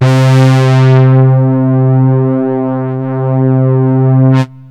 SYNTH LEADS-2 0005.wav